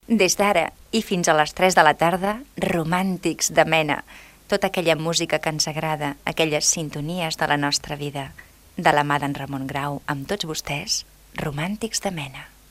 Veu de la careta del programa.
FM